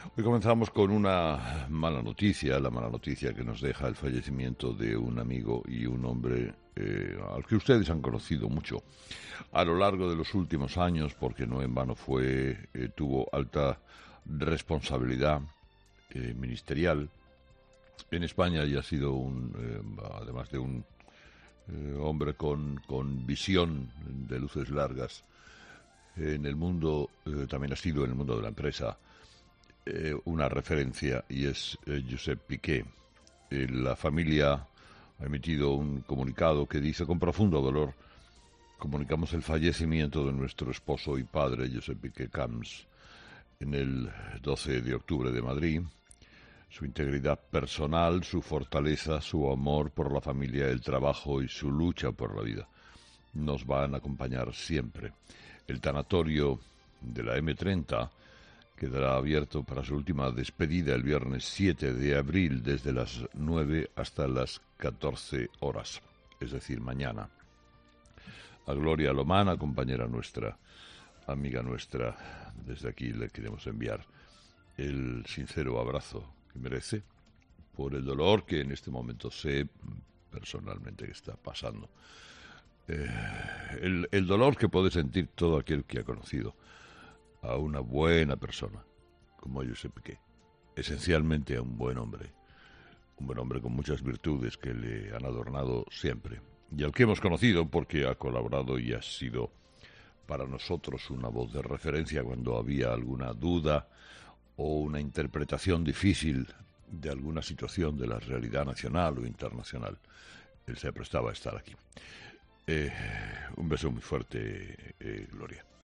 Carlos Herrera comunicaba la triste noticia y transmitía a los oyentes de 'Herrera en COPE' el comunicado de la familia